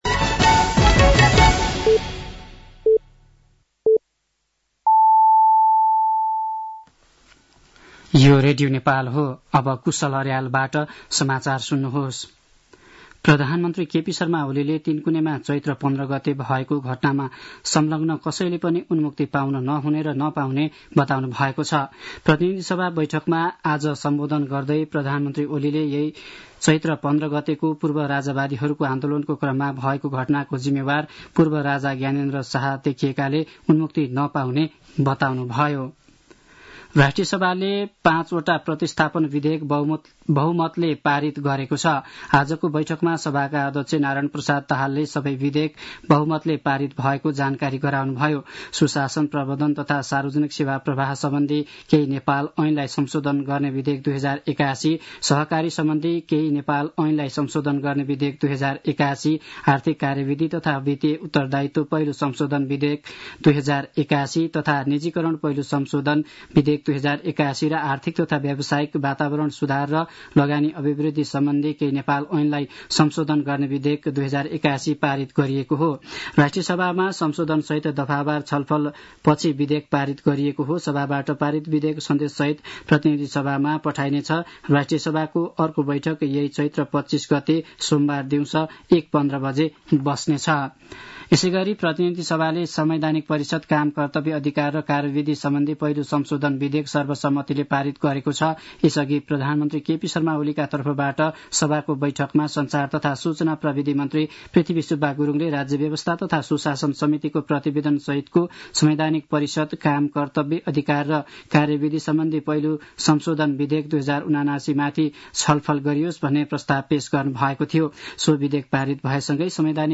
साँझ ५ बजेको नेपाली समाचार : १८ चैत , २०८१
5-pm-news-10.mp3